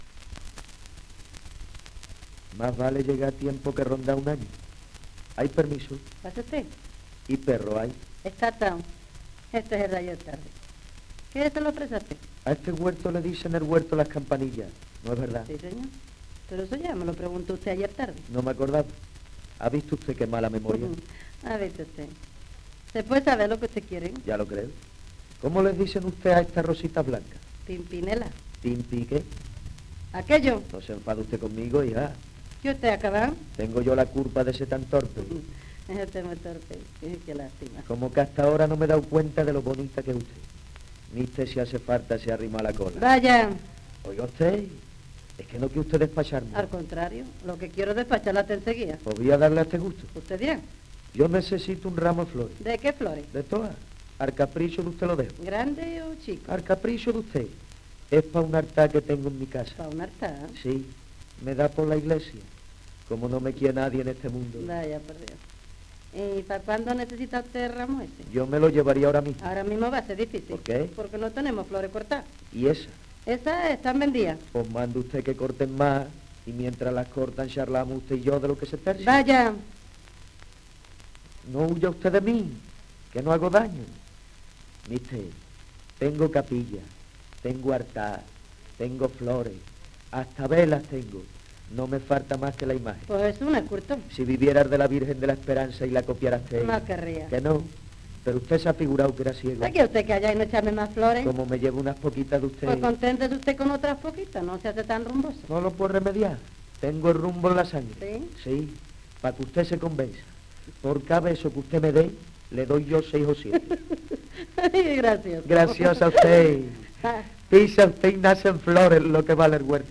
6 discos : 78 rpm ; 25 cm Intérprete: Concha Catalá y Antonio Vico Editorial: Fabricado por la Compañía del Gramófono